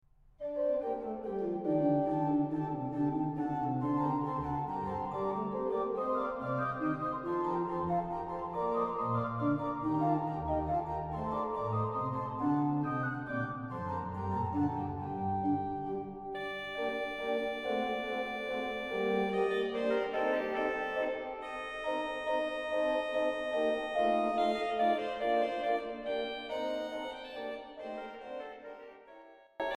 L’organo-orchestra Lingiardi 1877 di S. Pietro al Po in Cremona